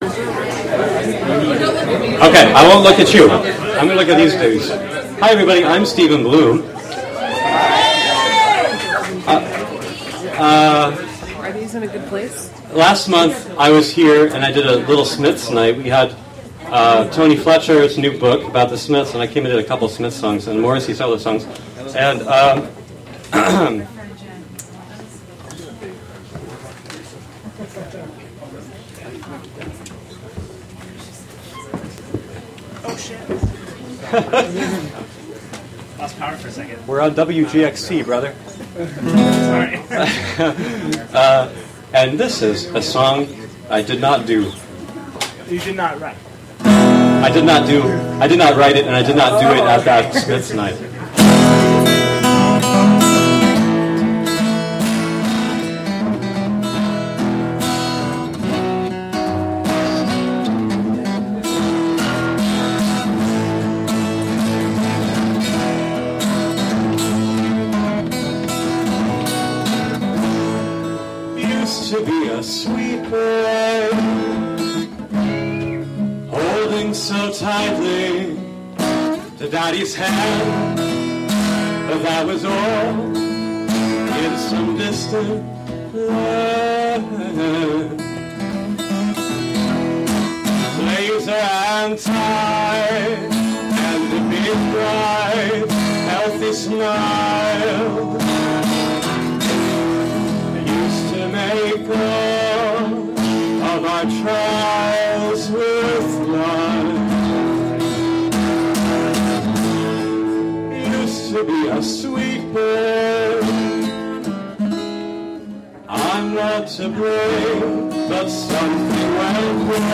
Live performances.
performance at Spotty Dog Books & Ale.
8pm- 11pm free103point9 Online Radio Recorded from a live free103point9 webstream and for WGXC broadcast.